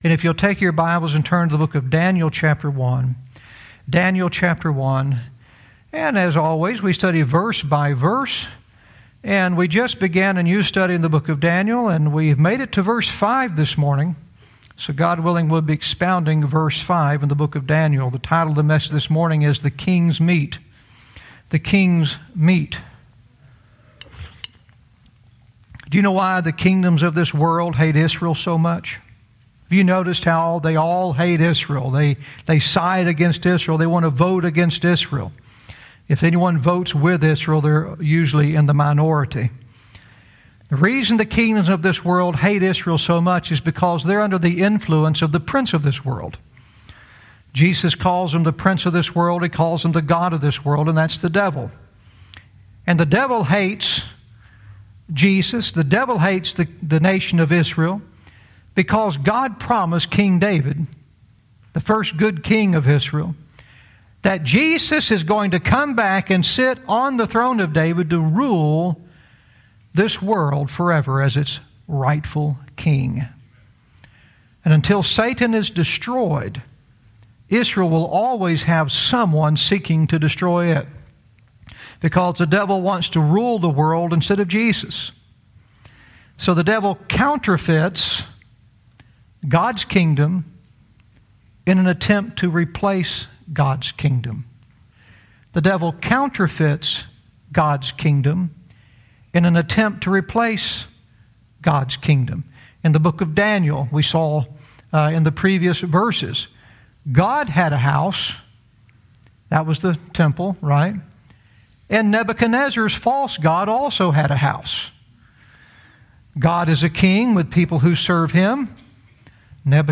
Verse by verse teaching - Daniel 1:5 "The King's Meat"